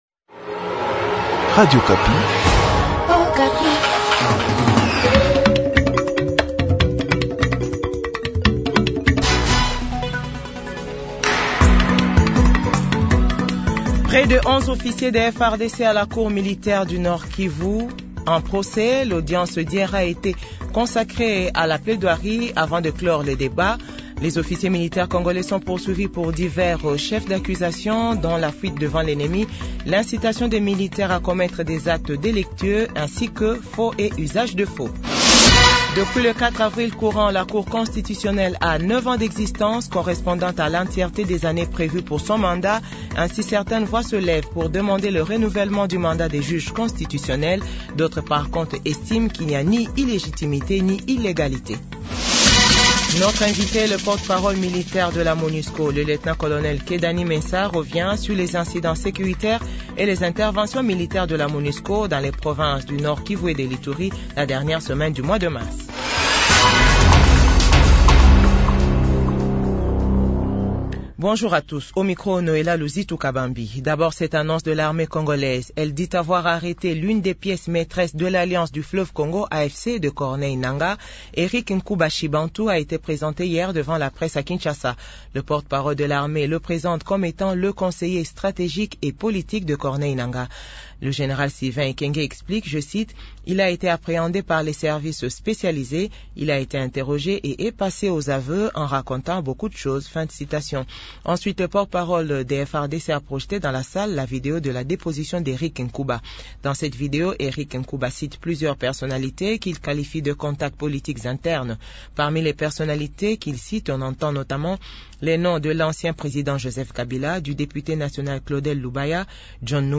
JOURNAL FRANCAIS 8H00